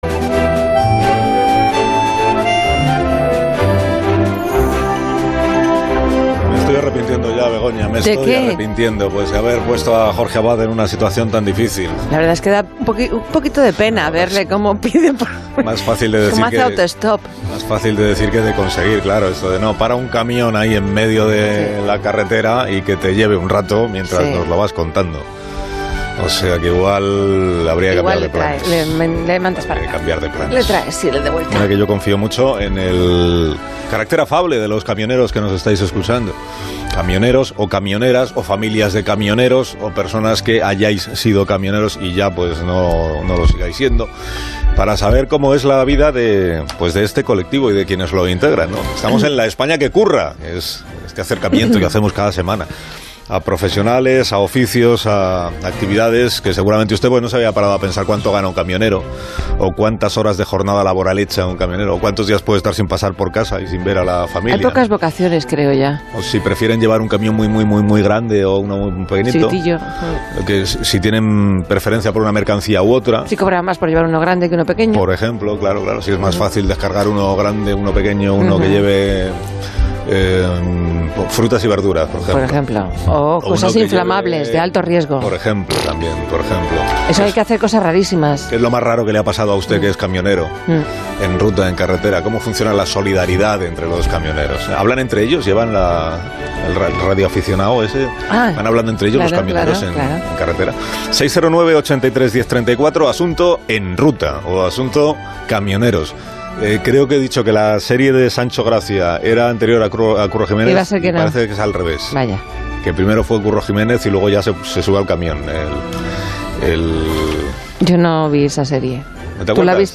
El programa matinal Más de Uno, de Onda Cero y presentado por Carlos Alsina, se ha adentrado en el mundo de los camioneros para intentar conocer sus historias, lo mejor y peor de una profesión bastante desconocida para la sociedad.